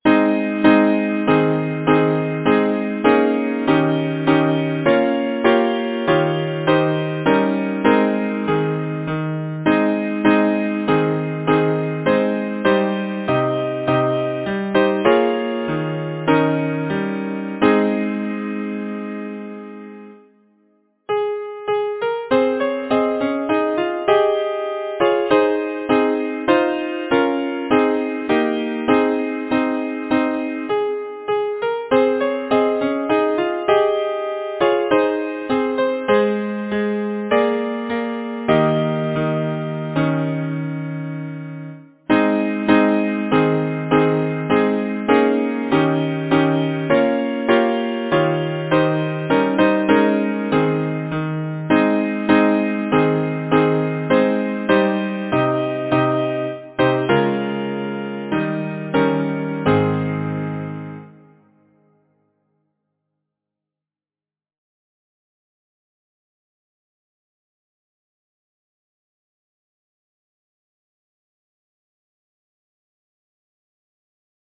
Title: A declaration Composer: Nathan Brown Sprague Lyricist: Bayard Taylor Number of voices: 4vv Voicing: SATB Genre: Secular, Partsong
Language: English Instruments: A cappella